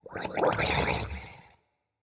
Minecraft Version Minecraft Version 1.21.5 Latest Release | Latest Snapshot 1.21.5 / assets / minecraft / sounds / mob / drowned / water / idle3.ogg Compare With Compare With Latest Release | Latest Snapshot